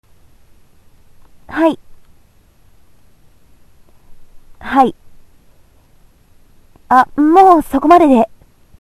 音声素材
声職人さん方から提供された音声素材です。